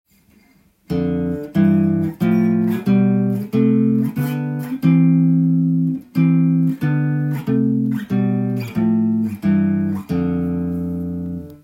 6度ハモリでスケールを弾いたTAB譜
6弦始まり
6度ハモリを使うとバロック音楽と言われるバッハのようなクラシカルな